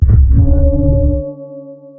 sonarPingAirClose1.ogg